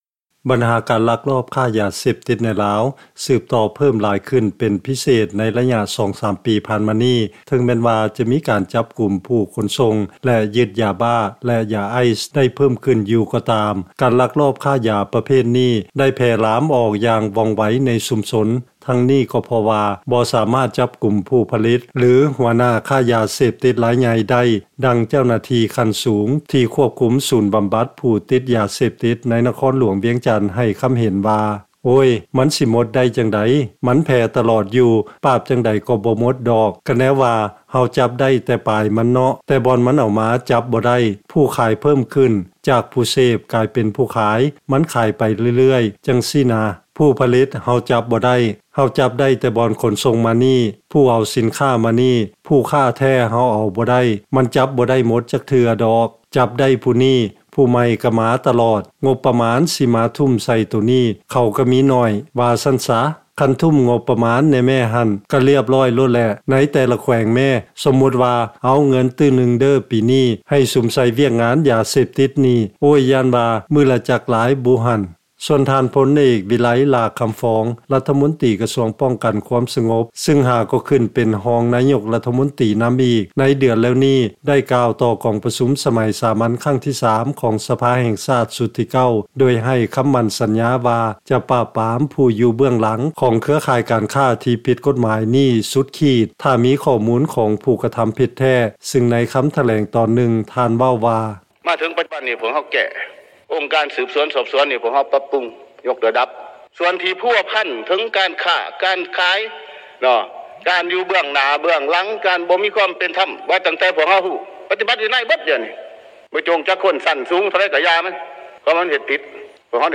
ເຊີນຟັງລາຍງານ ການລັກລອບຄ້າຢາເສບຕິດໃນລາວ ຍັງສືບຕໍ່ເພີ້ມຂຶ້ນ ເຖິງແມ່ນວ່າ ຈະມີການ ຈັບກຸມ ແລະຢຶດຢາເສບຕິດໄດ້ຢ່າງຫຼວງຫຼາຍກໍຕາມ